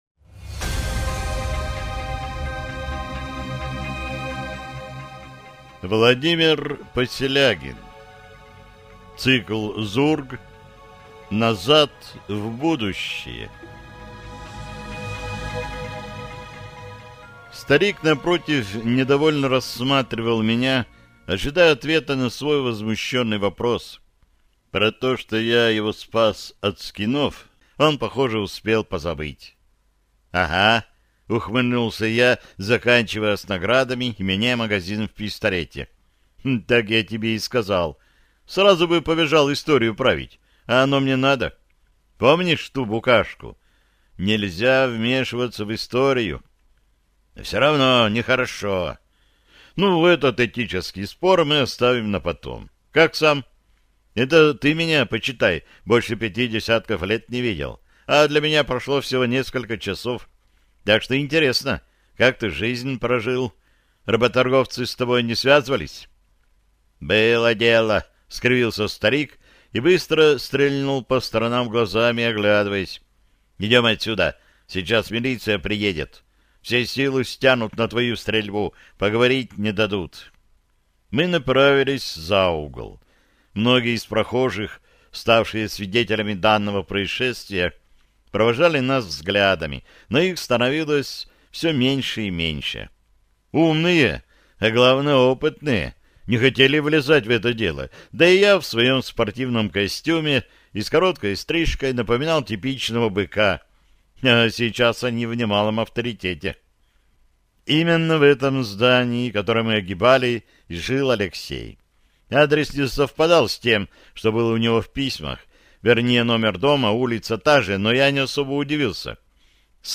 Аудиокнига Назад в будущее | Библиотека аудиокниг
Прослушать и бесплатно скачать фрагмент аудиокниги